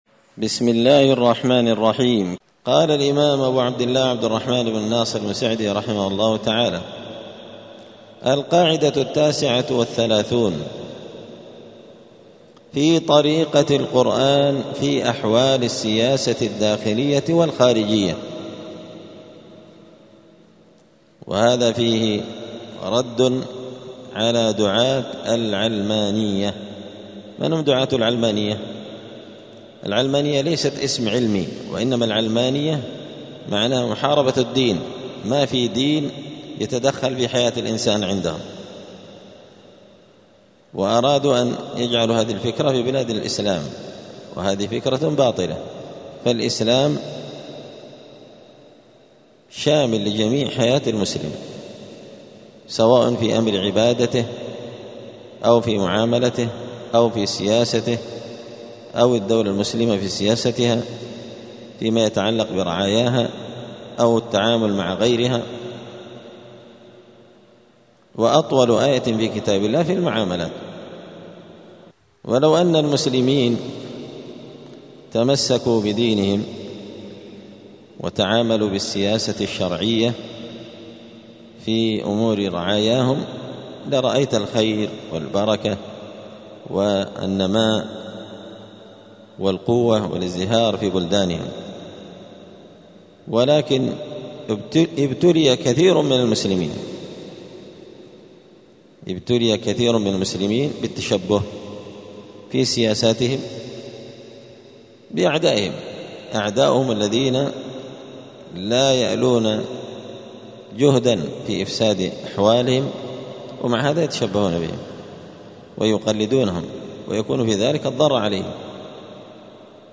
دار الحديث السلفية بمسجد الفرقان قشن المهرة اليمن